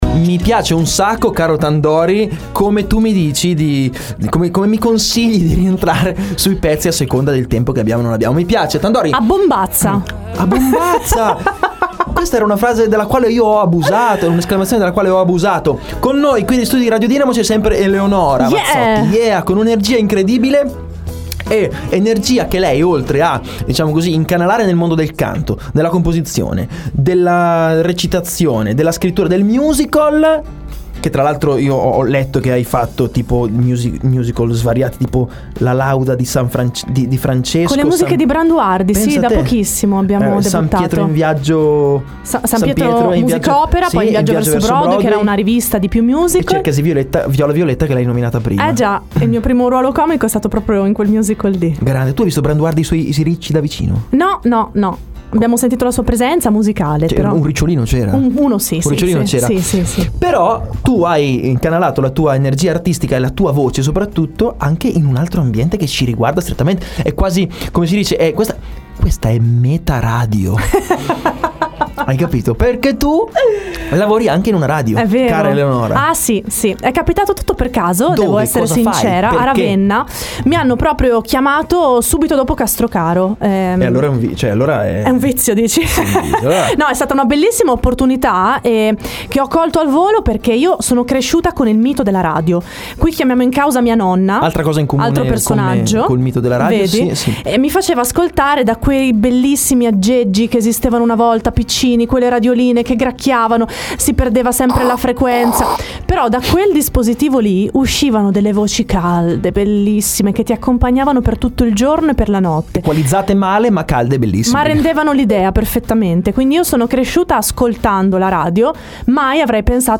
LA DIRETTA!!!